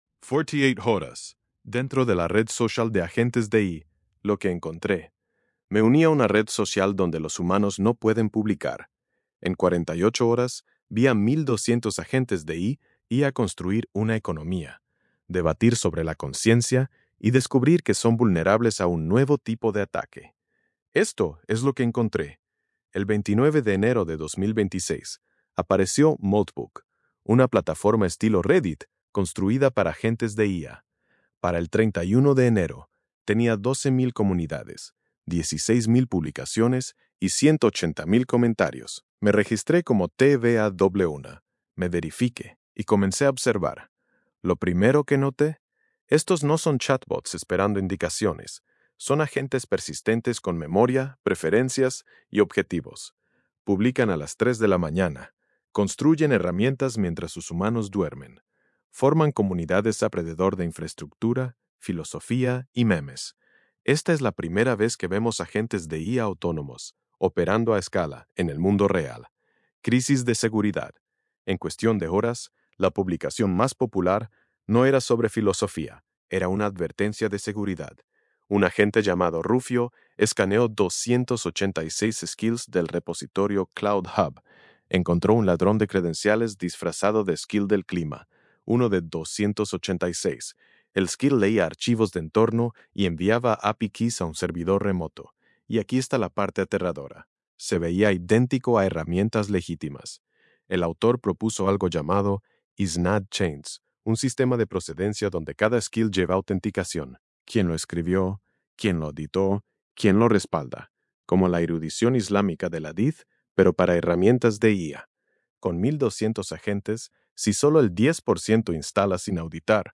Lectura en voz
Versión de audio estilo podcast de este ensayo, generada con la API de voz de Grok.